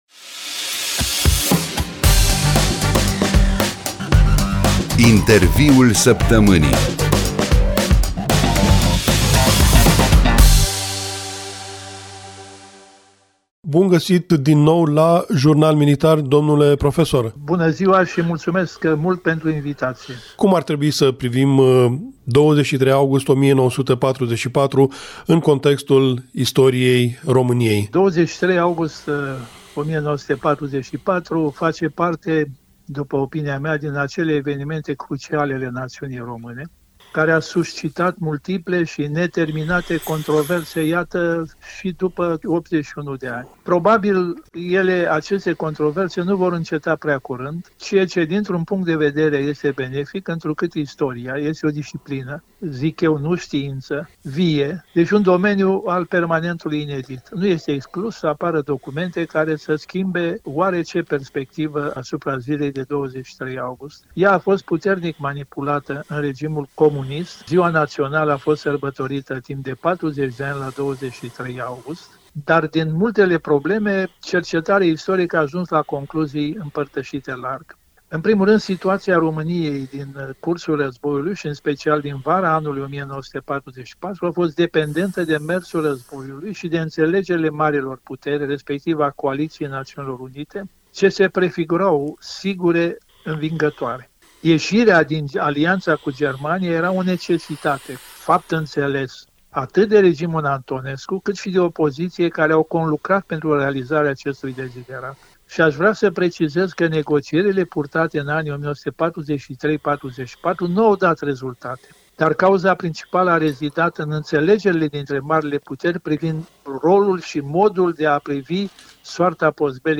Interviul Săptămânii